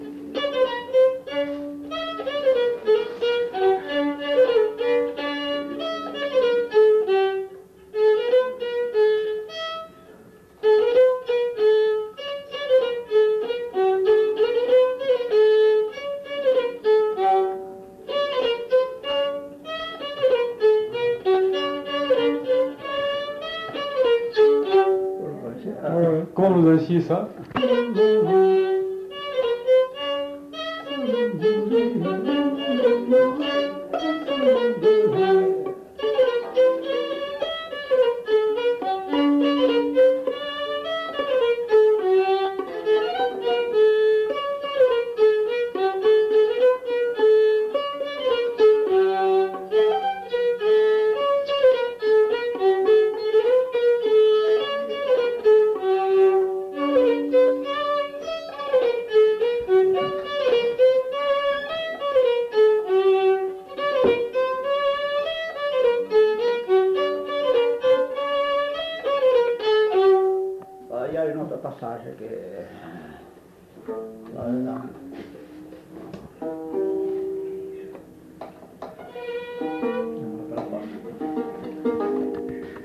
Bourrée
Lieu : Saint-Michel-de-Castelnau
Genre : morceau instrumental
Instrument de musique : violon
Danse : bourrée